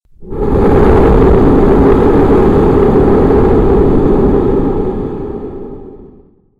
ドラゴン・魔獣・怪物
龍の咆哮
dragon_roar.mp3